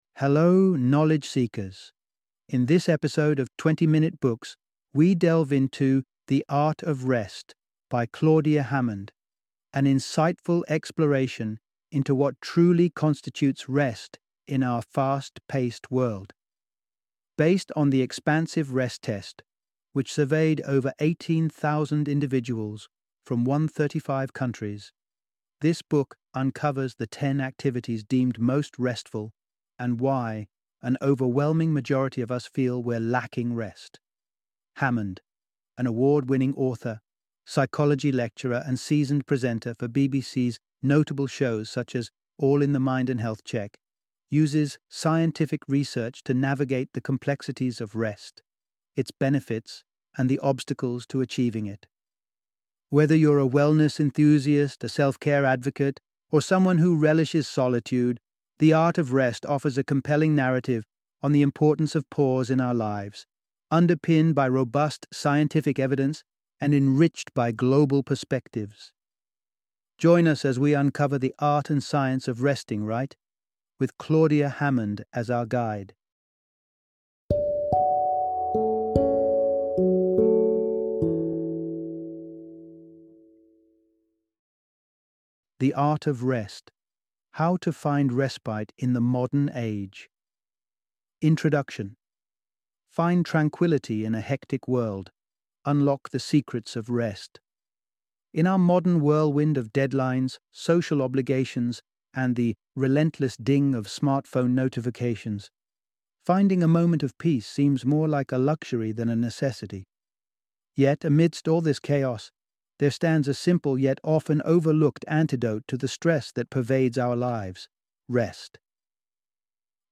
The Art of Rest - Book Summary